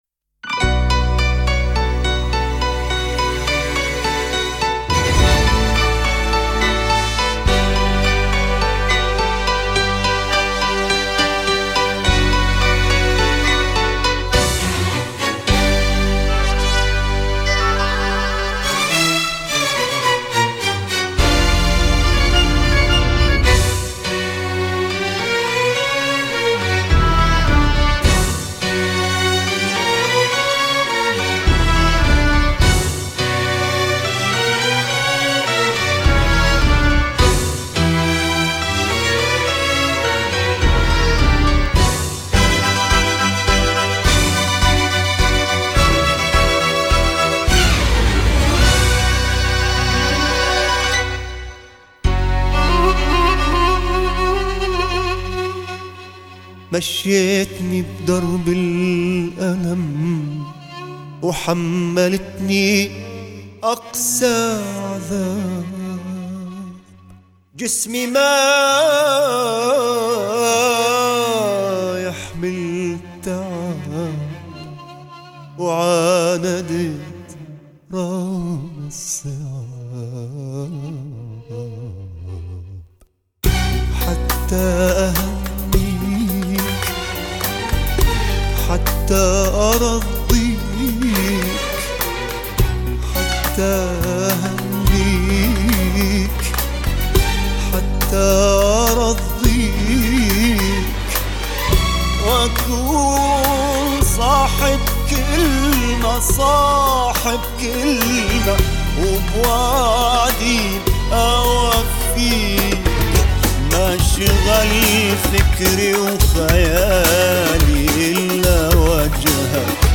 سبک موسیقی عربی و پاپ